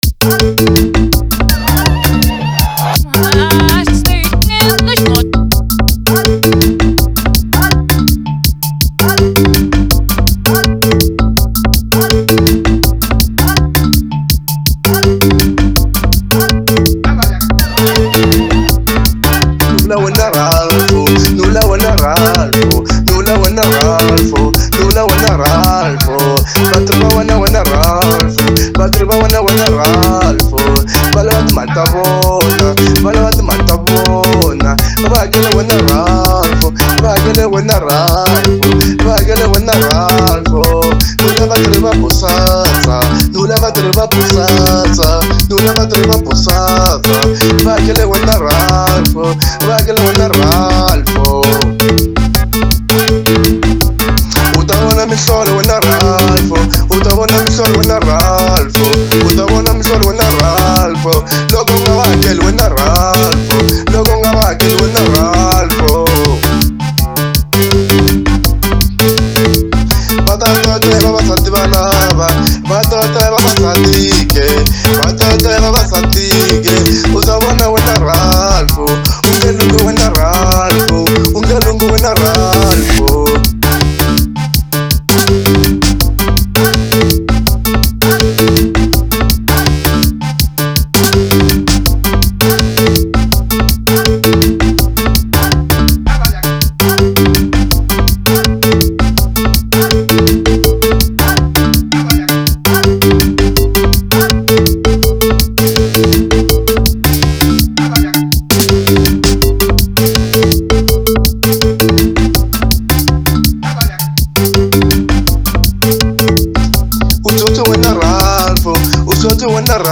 04:23 Genre : Xitsonga Size